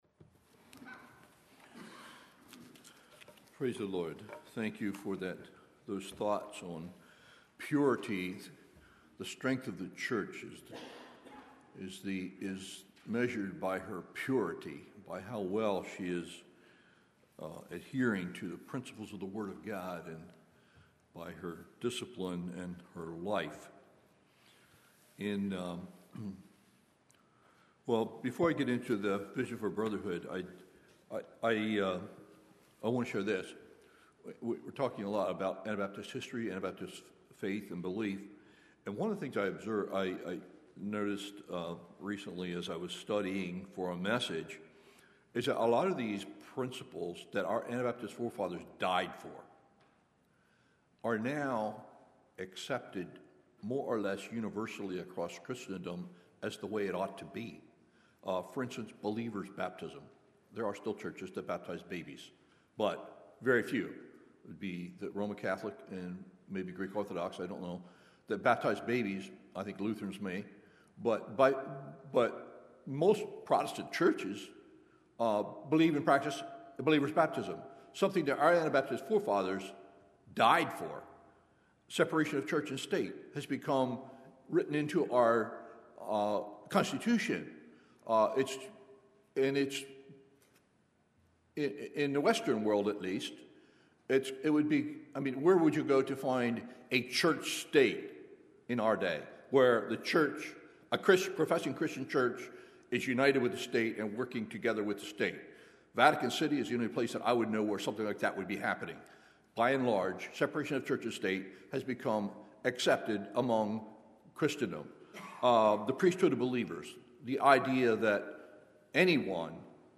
Sermons
Swatara | Spring Conference 2025